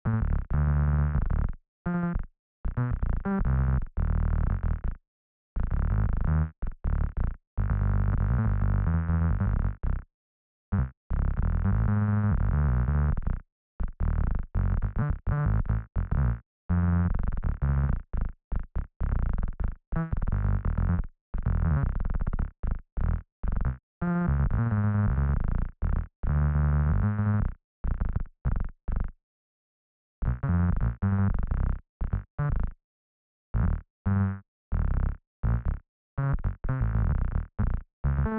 When I switch to MonoPhonic mode, there is a lot of noise triggering.
I think it is the background noise generated by the system.